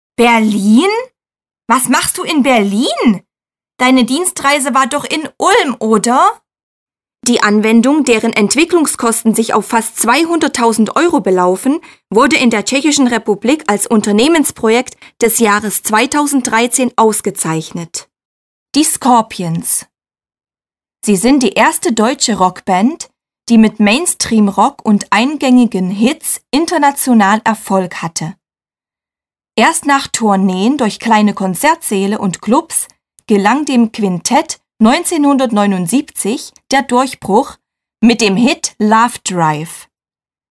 Vertonung, Synchronsprechen
• gute Artikulation
• notwendige Intonation und Ton
• angenehme Stimme